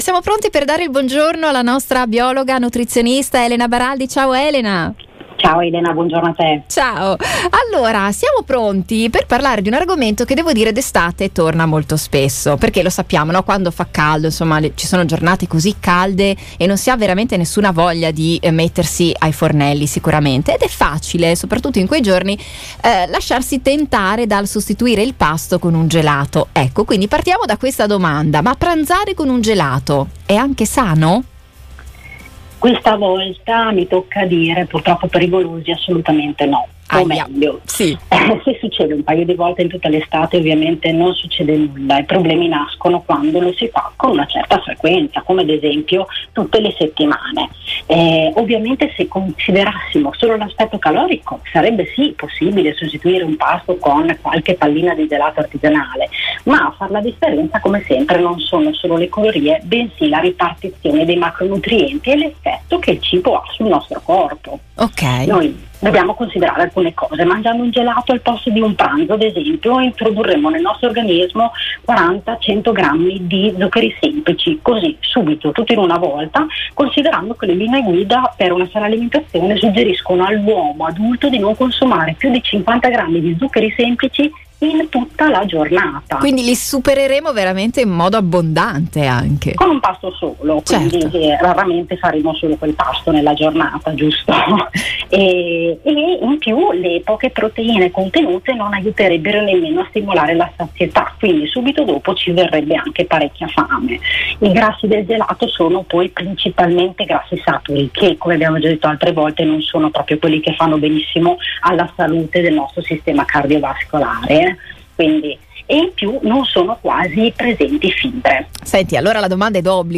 biologa nutrizionista